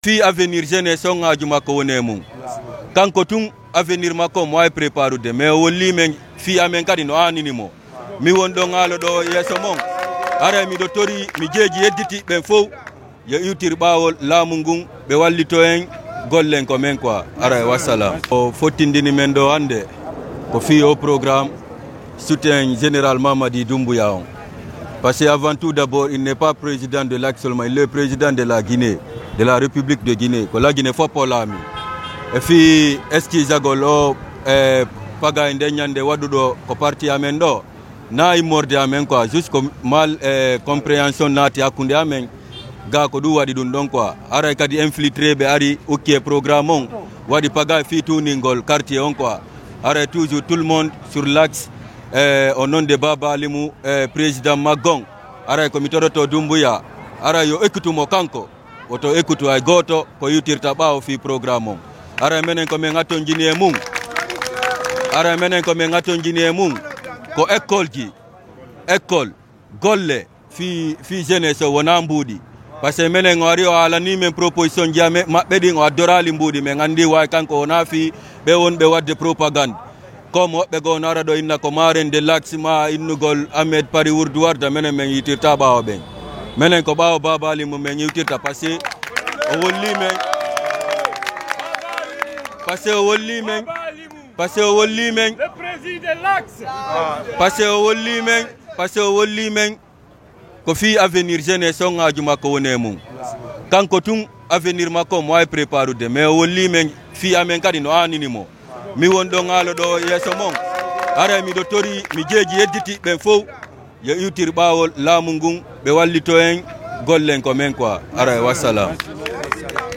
Le ton est humble, presque suppliant.